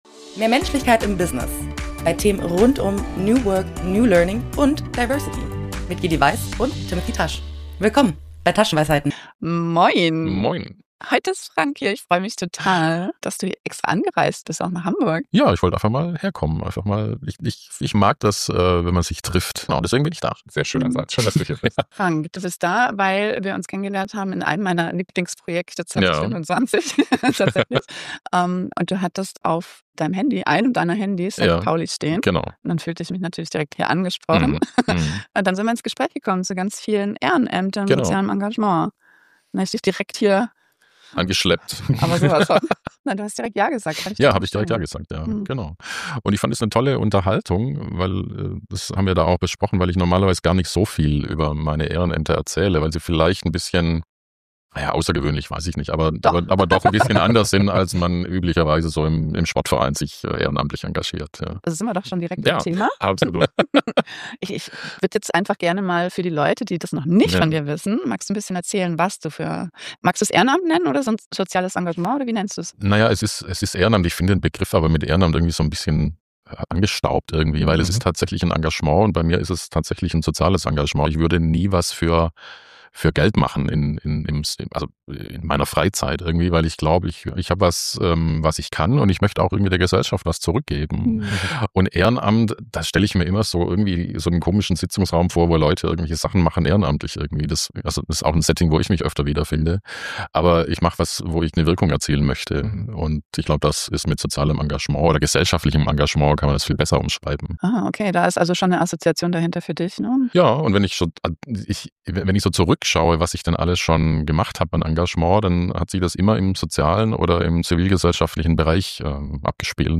Wenn drei engagierte Menschen miteinander im Gespräch über soziale Engagements sind, klingt „Ehrenamt“ plötzlich gar nicht mehr nach angestaubtem Sitzungsraum.